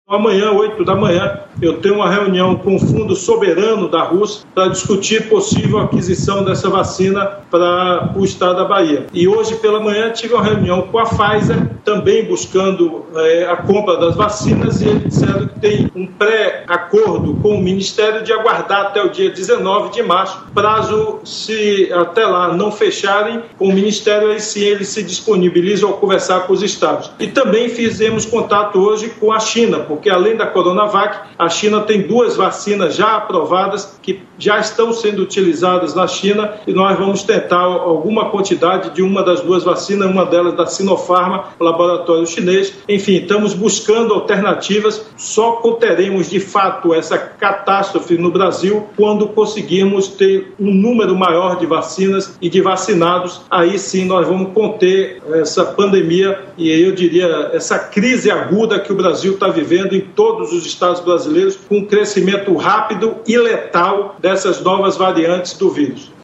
Nesta quinta-feira (25), em entrevista à BandNews TV, o governador Rui Costa disse que já manteve contato com representantes do laboratório Pfizer e com os fabricantes de vacinas da China. Ele também contou que terá reunião com o Fundo Soberano da Rússia, na manhã desta sexta-feira (26), para tratar da aquisição de doses da vacina Sputinik V. Na última terça-feira (23), o Supremo Tribunal Federal (STF) liberou a compra direta de vacinas pelo distrito federal, estados e municípios.